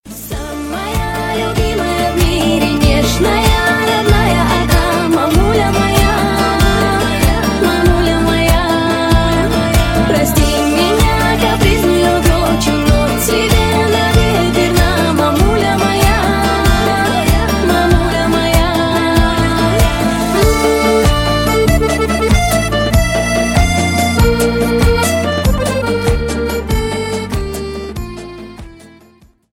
• Качество: 128, Stereo
поп
мелодичные
спокойные
лиричные